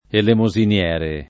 vai all'elenco alfabetico delle voci ingrandisci il carattere 100% rimpicciolisci il carattere stampa invia tramite posta elettronica codividi su Facebook elemosiniere [ elemo @ in L$ re ] (meno com. elemosiniero [ elemo @ in L$ ro ]) s. m.